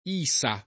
isa [ &S a ] → issa